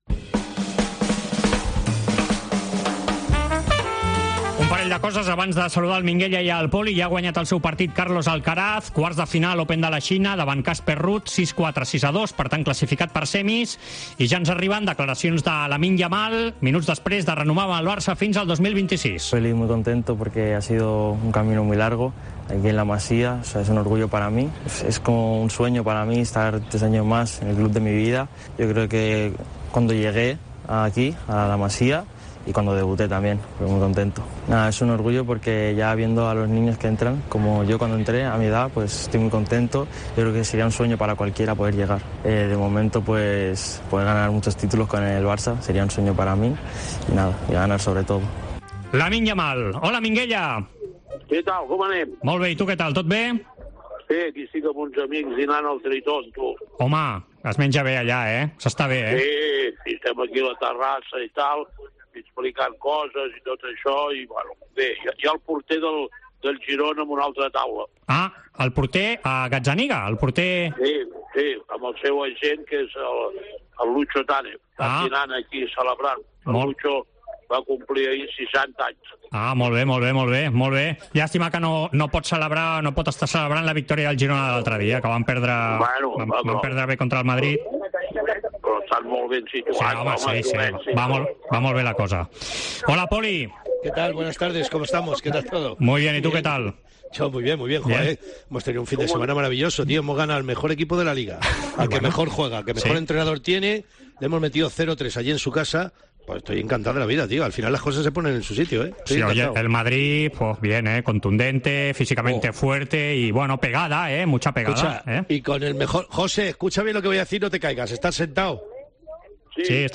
El debat Esports COPE, amb Poli Rincón i Minguella
AUDIO: Els dos col·laboradors de la Cadena COPE repassen l'actualitat esportiva d'aquesta setmana.